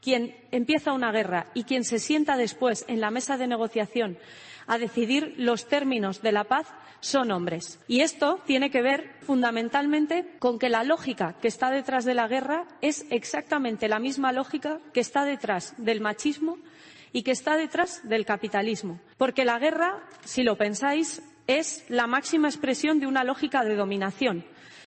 Así se ha expresado este sábado la líder de Podemos durante su intervención en Encuentro Internacional Feminista 'We Call It Feminism', organizado por el Ministerio de Igualdad, en el que ha asegurado que toca "repensar" la estrategia de España con respecto a la guerra en Ucrania y reconocer que "haber contribuido a la escalada bélica ha sido un error que no ha servido para ayudar al pueblo ucraniano".
"Cuando escuchamos hablar de que tenemos que hacer más esfuerzos para contribuir a esta guerra, las feministas defendemos que España no necesita ni más tanques, ni más aviones, ni más soldados, sino invertir en más médicos, profesores y más ayuda a domicilio", ha expresado Belarra arrancando el aplauso de los allí presentes.